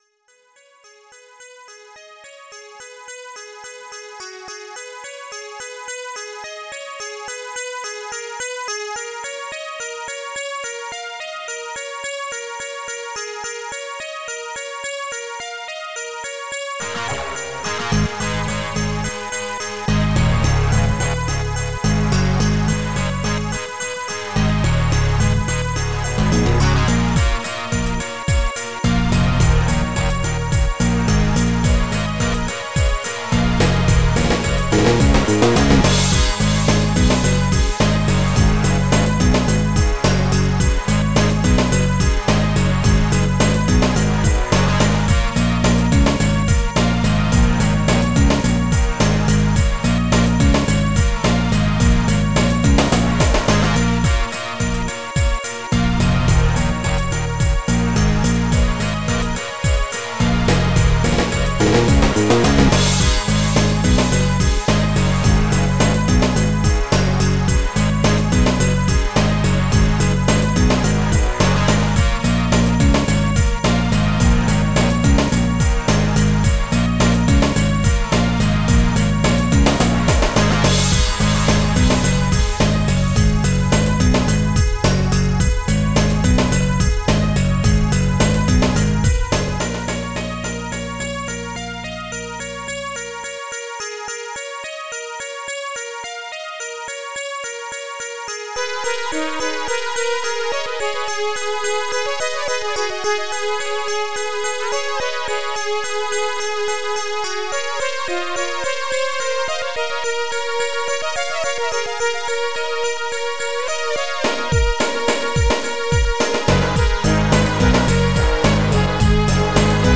Impulse Tracker Module  |  1998-06-12  |  451KB  |  2 channels  |  44,100 sample rate  |  5 minutes, 17 seconds
MultiChannel Compo